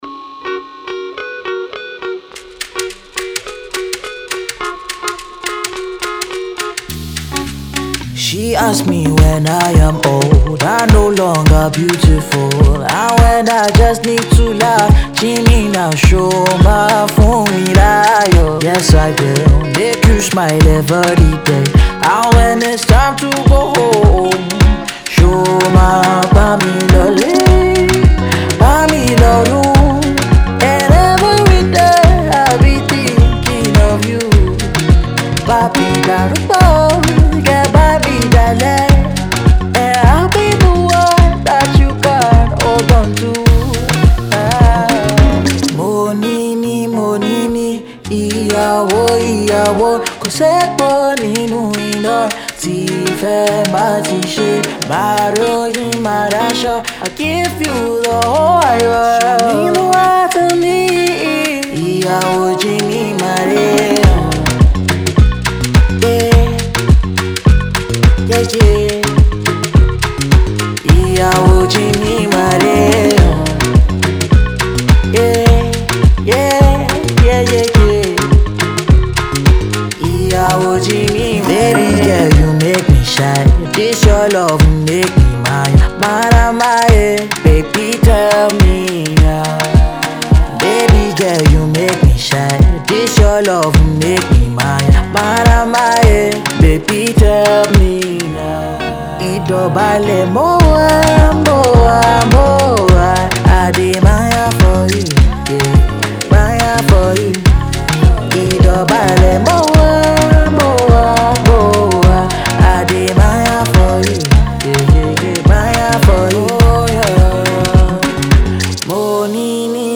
love anthem